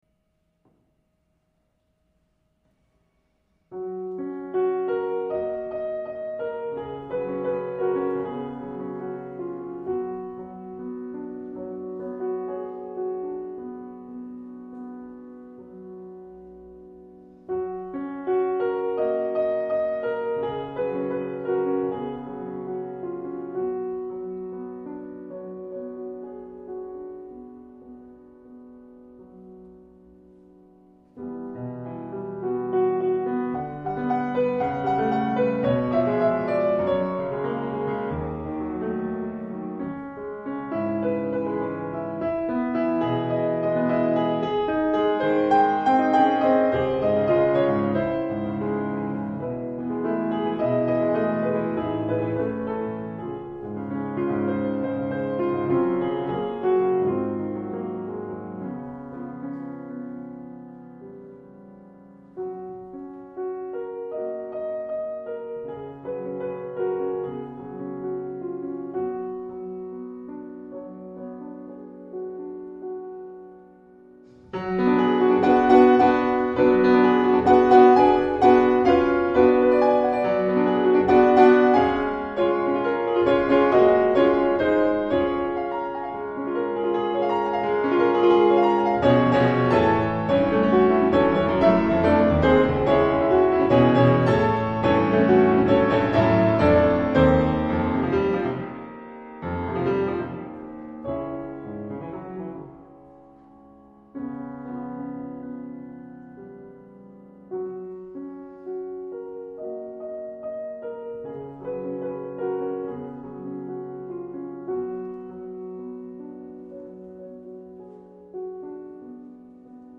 * Solo:
Leos Janacek, "Im Nebel", 4 Klavierstücke, Nr. 3 – LIVE